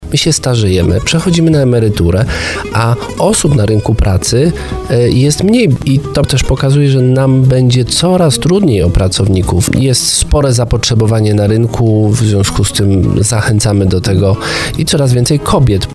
Miejskie Przedsiębiorstwo Komunikacyjne w Tarnowie poszukuje nowych kierowców autobusów. Jak podkreślił na antenie RDN Małopolska prezydent miasta Jakub Kwaśny, główną przyczyną naboru są zmiany demograficzne – wielu dotychczasowych pracowników wkrótce przejdzie na emeryturę.